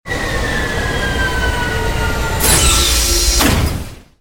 shipLand.wav